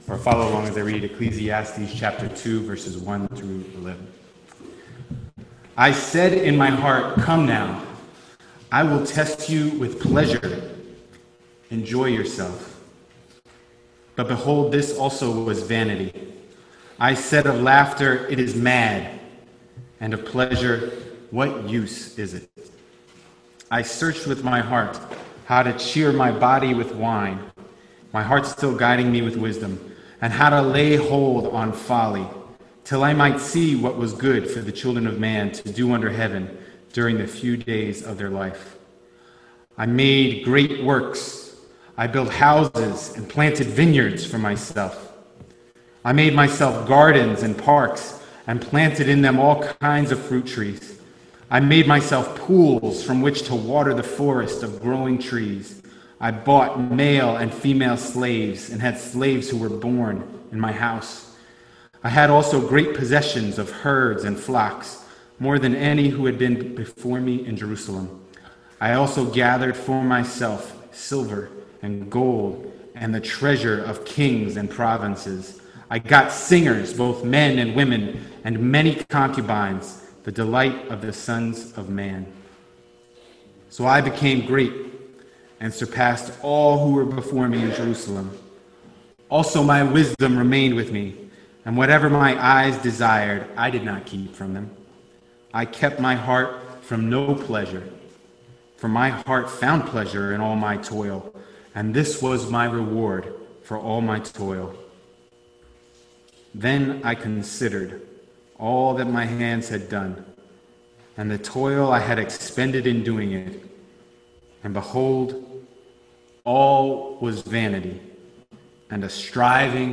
Ecclesiastes Passage: Ecclesiastes 2:1-11 Service Type: Sunday Morning « The Examined Life